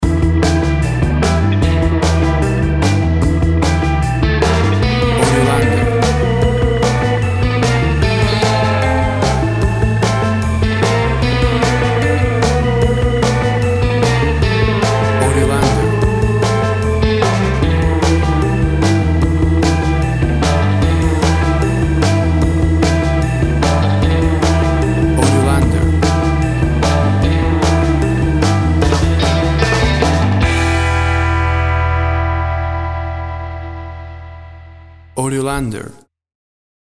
Tempo (BPM) 150